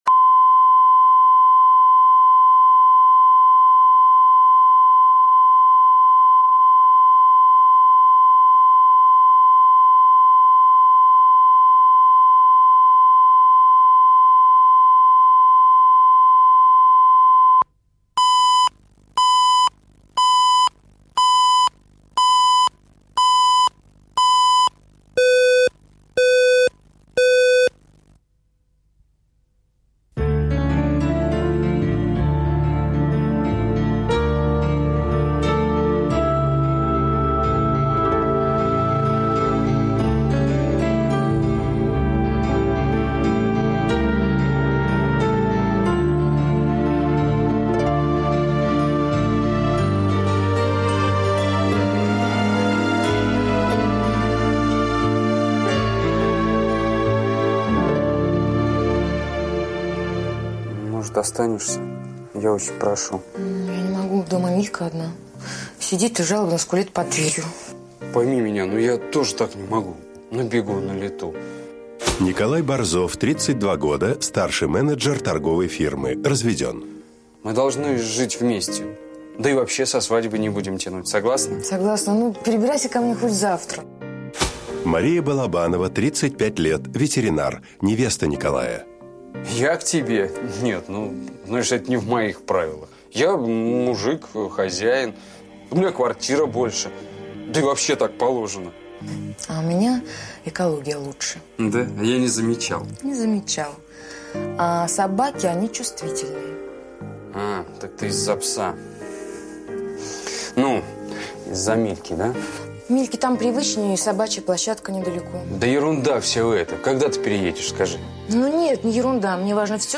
Аудиокнига Поводок | Библиотека аудиокниг
Прослушать и бесплатно скачать фрагмент аудиокниги